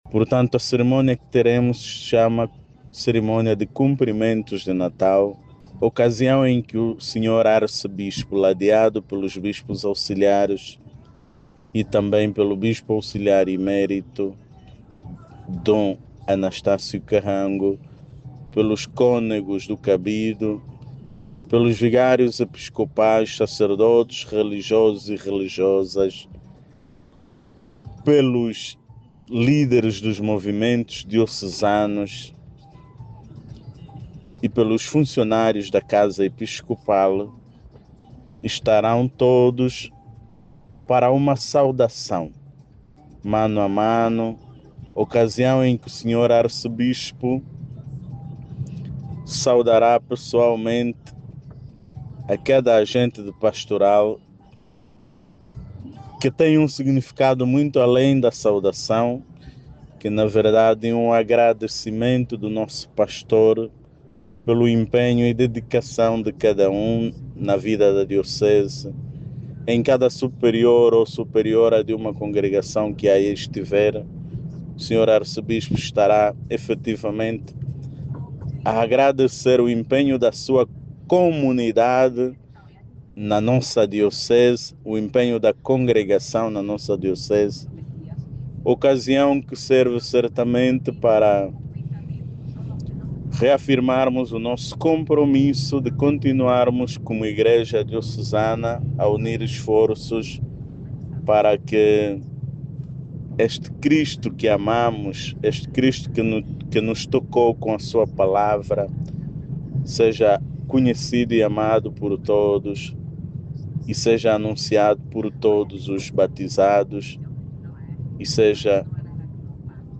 A Arquidiocese de Luanda reúne hoje para a cerimónia de comprimentos de natal os missionários ao serviço da igreja da capital